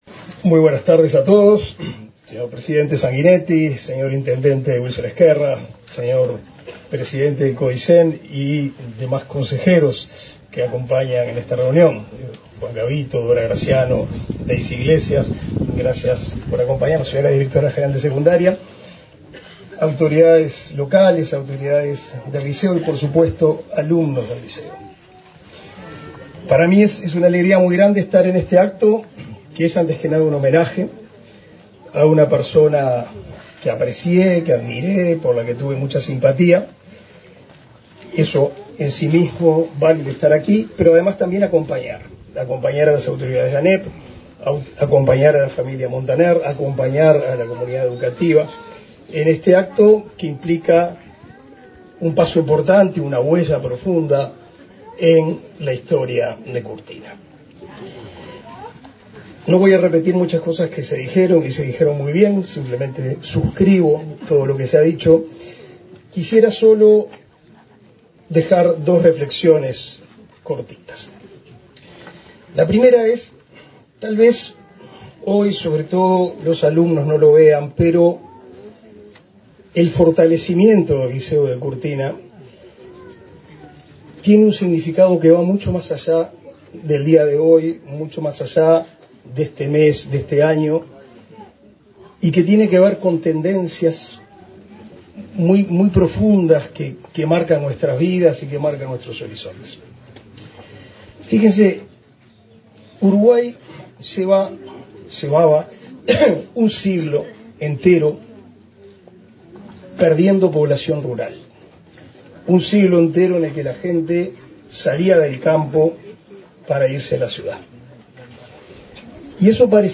Palabras del ministro de Educación y Cultura, Pablo da Silveira
Palabras del ministro de Educación y Cultura, Pablo da Silveira 09/06/2023 Compartir Facebook X Copiar enlace WhatsApp LinkedIn Las autoridades de la Administración Nacional de Educación Pública (ANEP) realizaron, este 9 de junio, la nominación del liceo rural de Curtina con el nombre de Dra. Marta Montaner Formoso, y presentaron el proyecto del nuevo liceo. Participó del evento el ministro de Educación y Cultura, Pablo da Silveira.